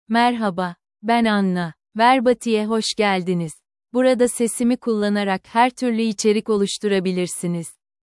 Anna — Female Turkish (Turkey) AI Voice | TTS, Voice Cloning & Video | Verbatik AI
Anna is a female AI voice for Turkish (Turkey).
Voice sample
Listen to Anna's female Turkish voice.
Anna delivers clear pronunciation with authentic Turkey Turkish intonation, making your content sound professionally produced.